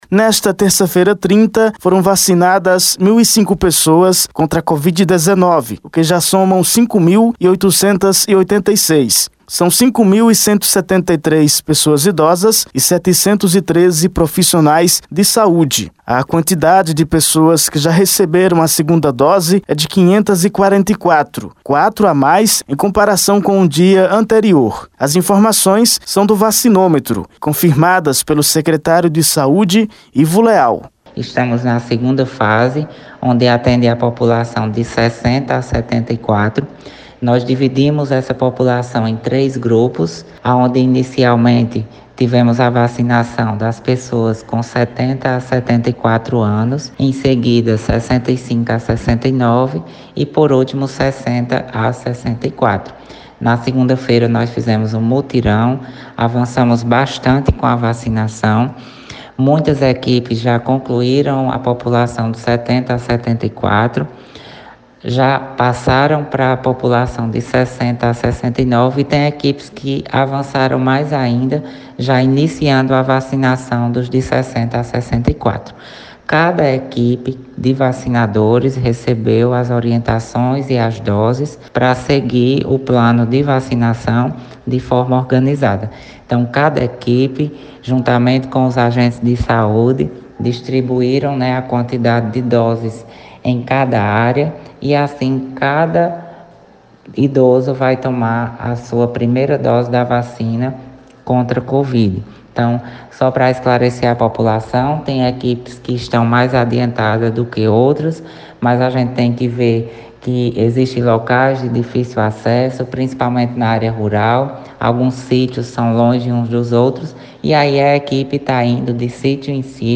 Confira a reportagem de áudio: foto redes sociais